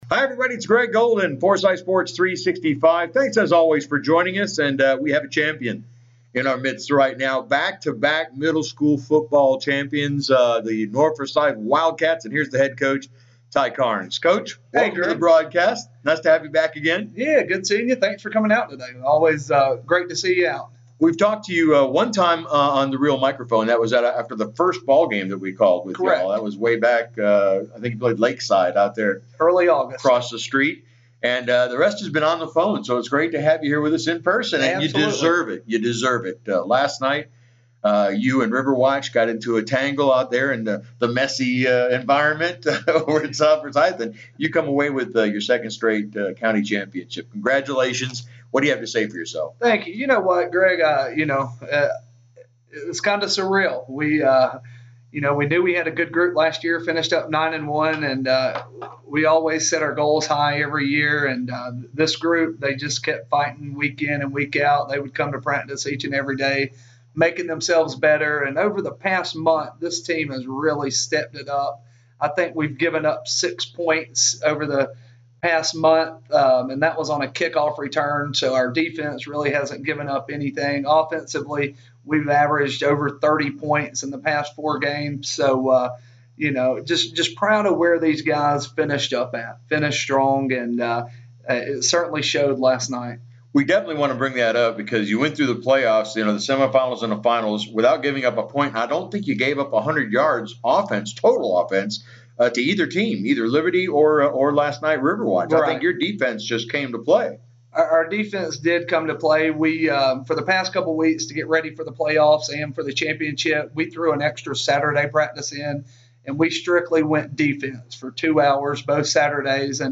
Enjoy the interview here, exclusively on Forsyth Sports 365. http